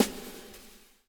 SND DRUMS -R.wav